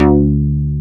RDBASSA2.wav